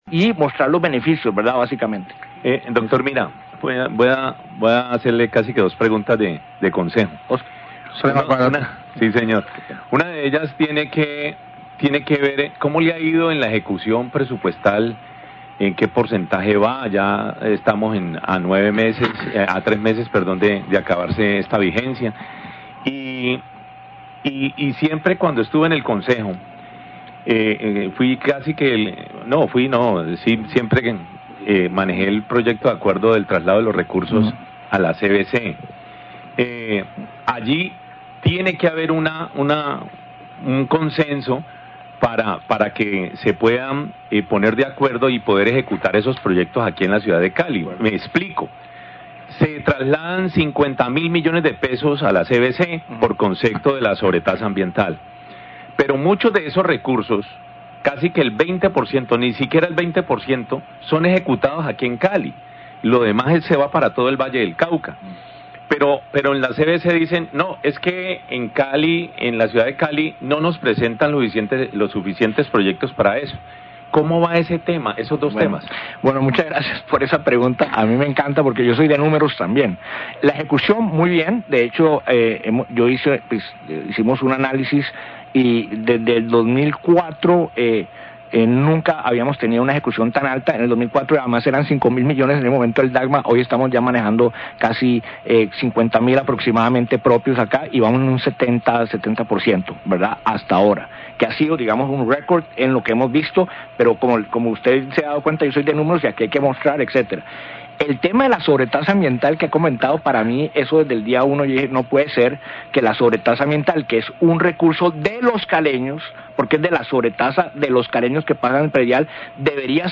Radio
entrevistas
Entrevista al director del DAGMA, Mauricio Mira, se refirió a la sobretasa ambiental y la ejecución de esos recursos por parte de la CVC en Cali.  Manifestó que el DAGMA debería tener la gobernanza sobre los recursos que aportan los caleños.